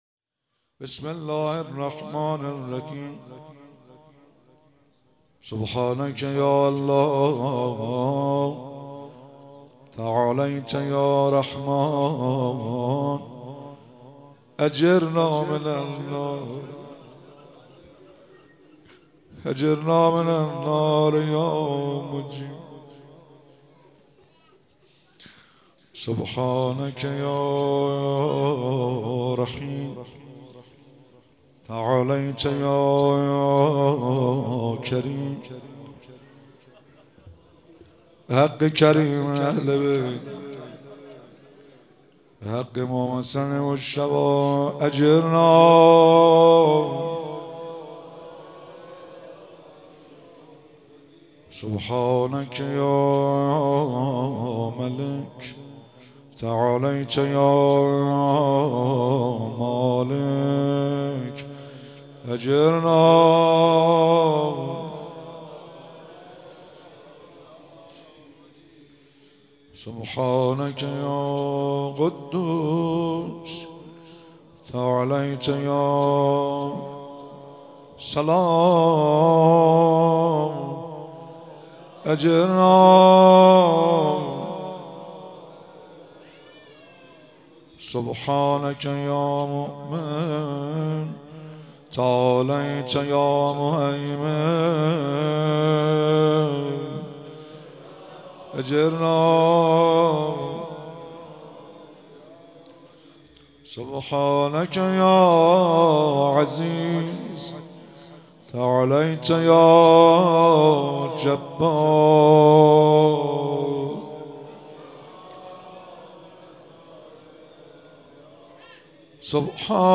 مراسم مناجات خوانی شب پانزدهم و جشن ولادت امام حسن مجتبی علیه السلام ماه رمضان 1444